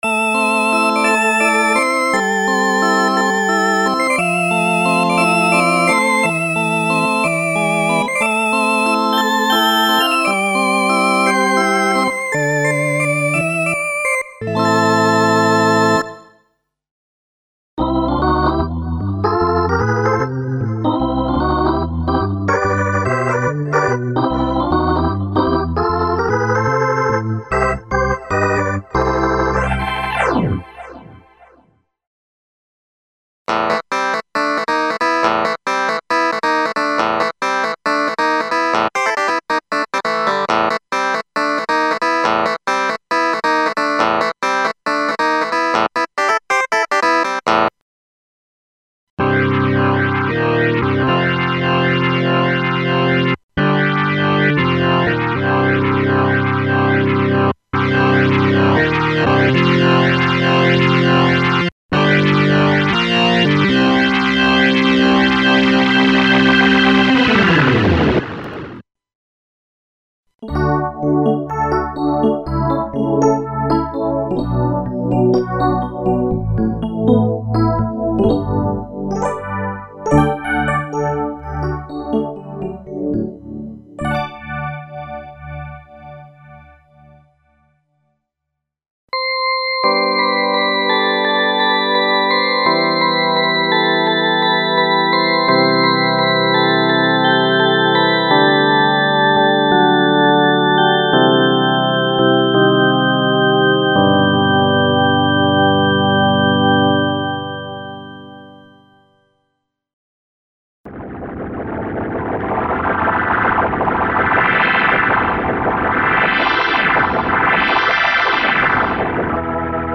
Comprehensive soundware collection of various classical and specially modulated types of clavinets, electric pianos and electric organs including the tonewheel organ emulations based on Kurzweil PC3´s KB3 Mode.
This category contains clavinet emulations including various filtered and specially modulated clavinet sound programs.
This soundware category contains the classical Rhodes, Wurlitzer, Yamaha CP80, Hohner Pianet and FM electric pianos including the experimental electric piano emulations.
This category contains vintage electric organs including filtered, specially modulated and distorted organ sounds for various music styles.
PC3-Works - Electro (Kurzweil PC3).mp3